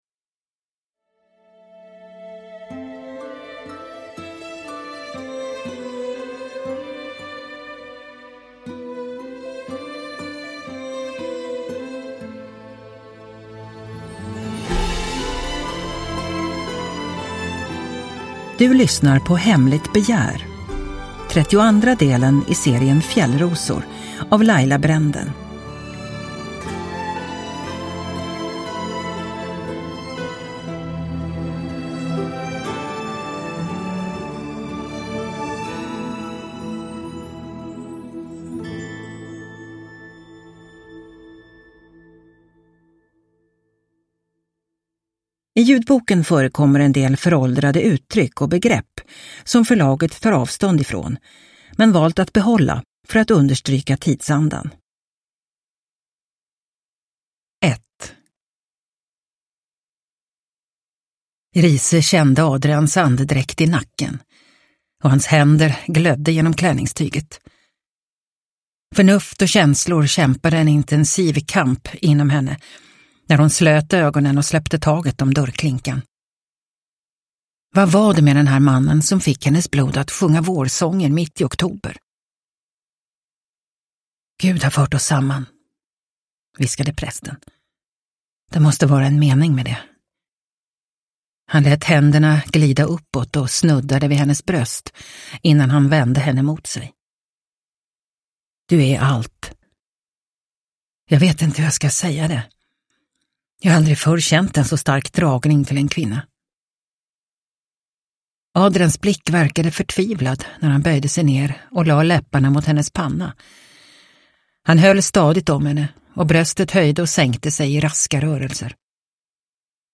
Hemligt begär – Ljudbok – Laddas ner